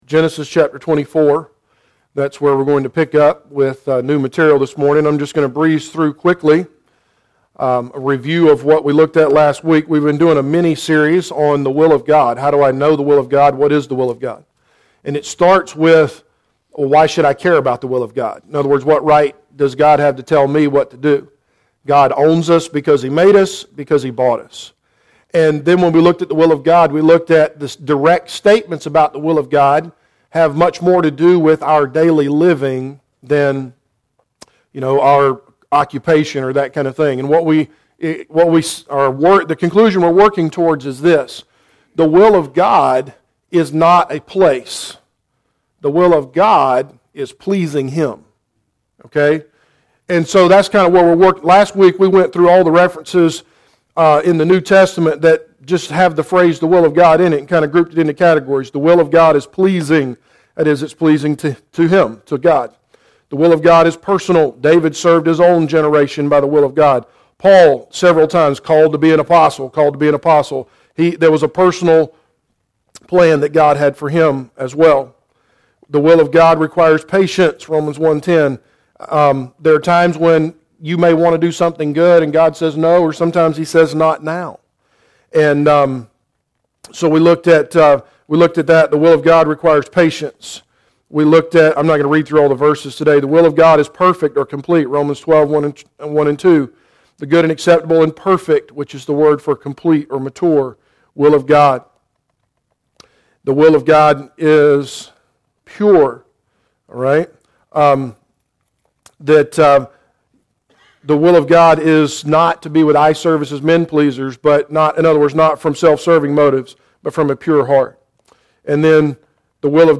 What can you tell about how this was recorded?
The Will of God Service Type: Adult Sunday School Class Preacher